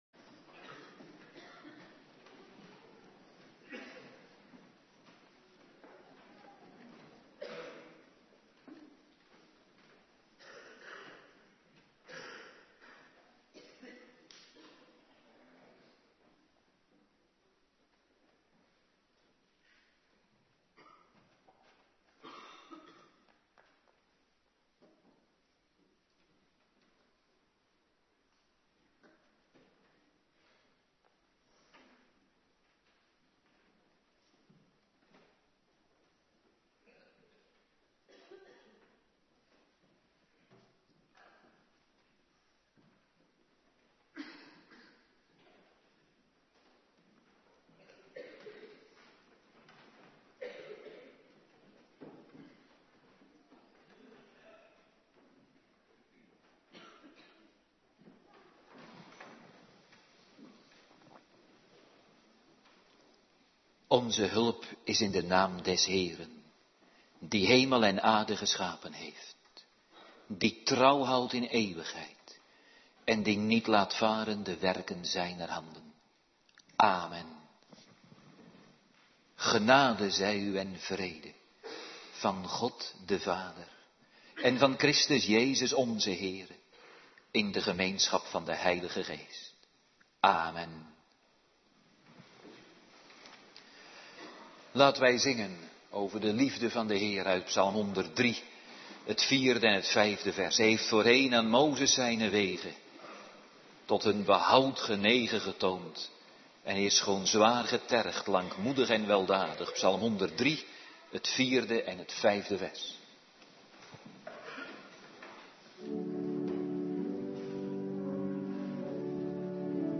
Morgendienst
09:30 t/m 11:00 Locatie: Hervormde Gemeente Waarder Agenda: Kerkdiensten Terugluisteren Hosea 1 en 3